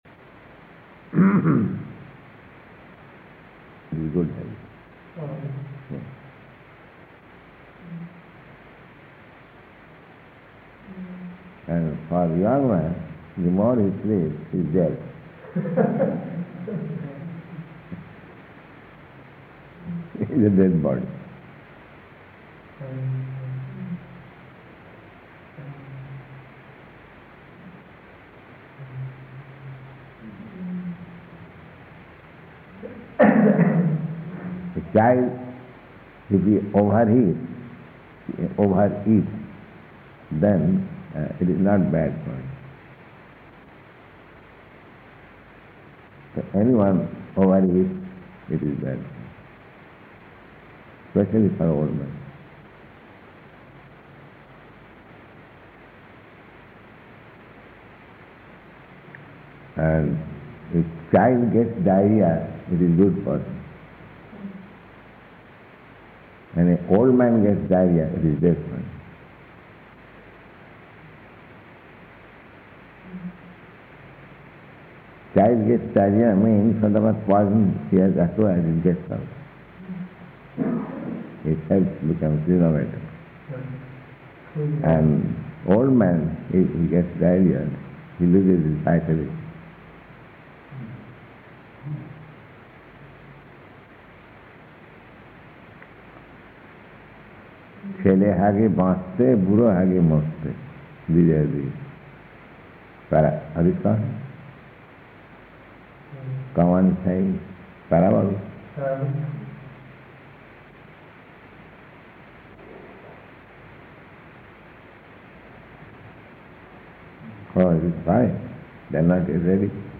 Śrīmad-Bhāgavatam 6.1.21 & Room Conversation
Śrīmad-Bhāgavatam 6.1.21 & Room Conversation --:-- --:-- Type: Srimad-Bhagavatam Dated: December 12th 1970 Location: Indore Audio file: 701212SB-INDORE.mp3 Prabhupāda: In good health?